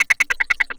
41 GIT01FX-R.wav